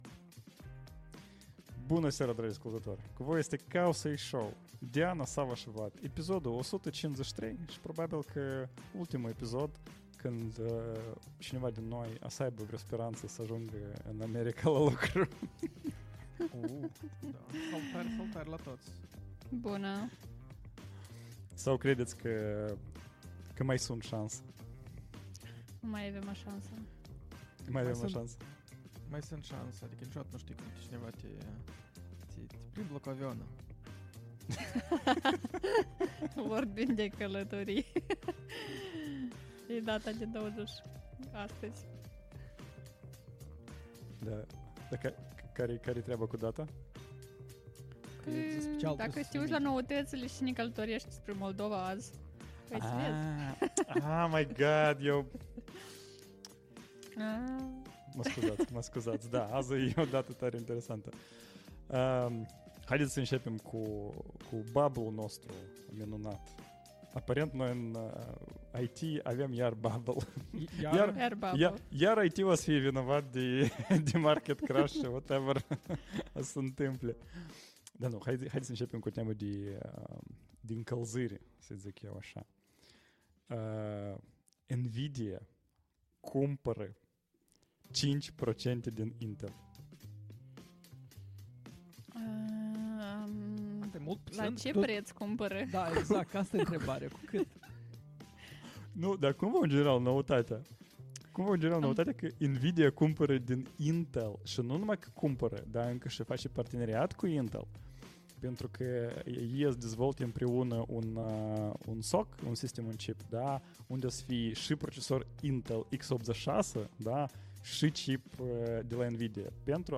Live 153: Caut sponsor pentru viză September 26th, 2025 Live-ul săptămânal Cowsay Show.